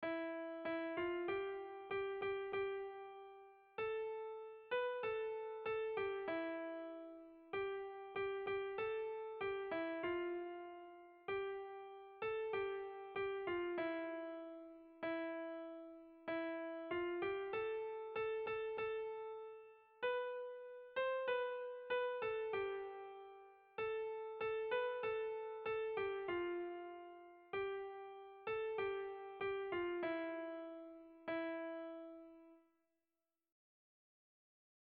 Sentimenduzkoa
Zortziko txikia (hg) / Lau puntuko txikia (ip)
ABDE